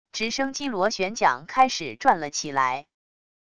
直升机螺旋桨开始转了起来wav音频